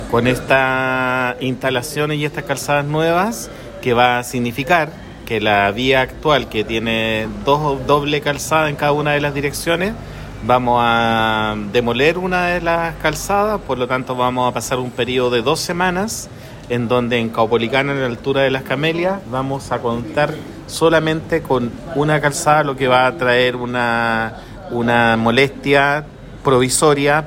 Así lo dijo el director del Serviu en la región, José Luis Sepúlveda.